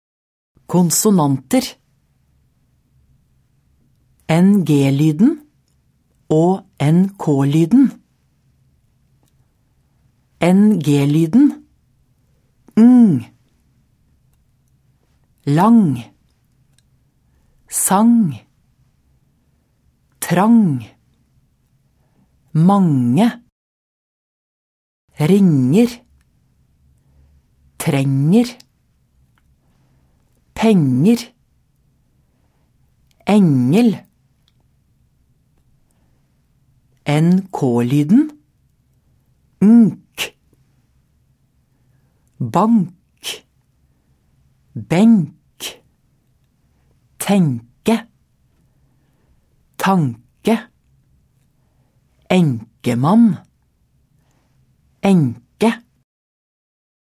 Uttale: ng-lyden og nk-lyden (s. 105)